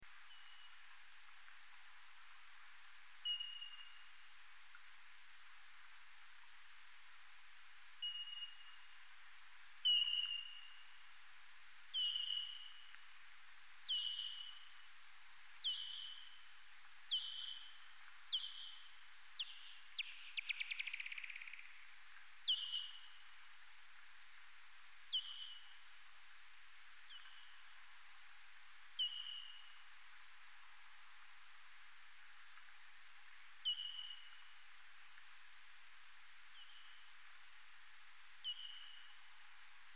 Nordfladdermus
Eptesicus nilssonii     Enil
Stabiliserad rytm: intervall kring 200 ms.
Pulsernas amplitudmaximum ligger ofta kring 30 kHz. Kan gå ner till 27 kHz, dock aldrig till 25 kHz
Fångstsurret: